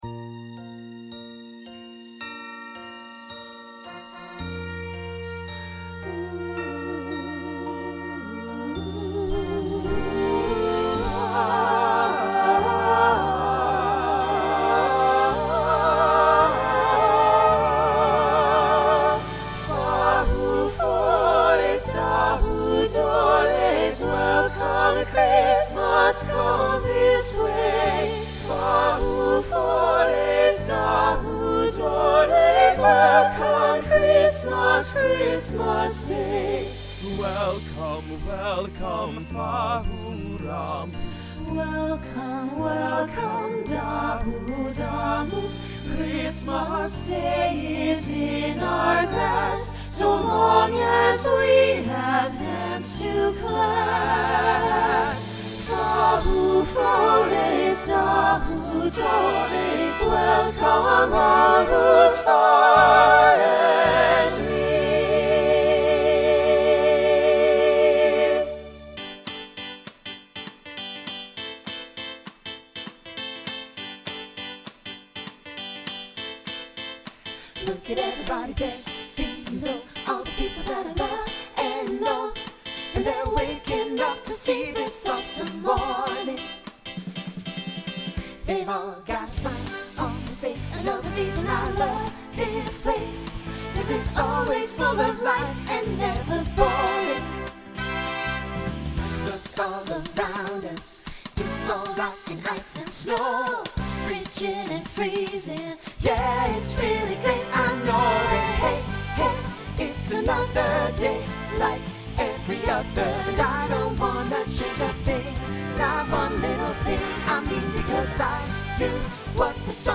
Broadway/Film , Holiday , Pop/Dance
Voicing SATB